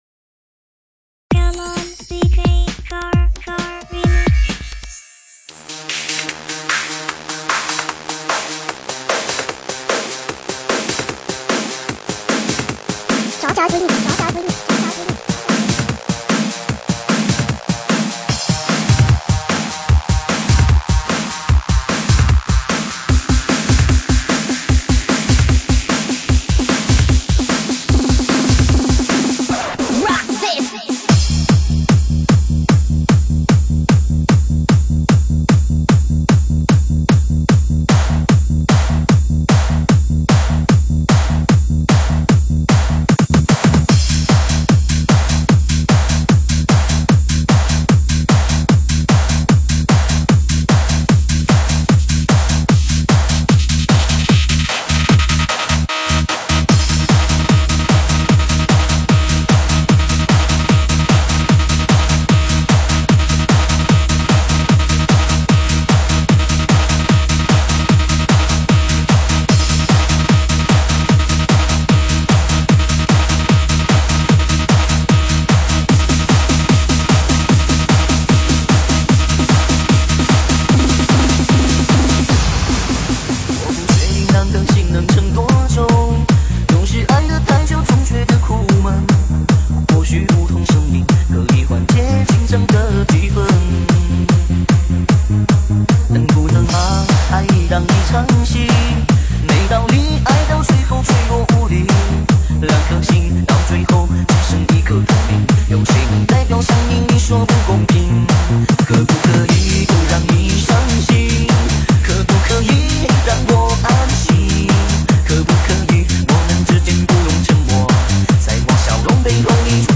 栏目： 现场串烧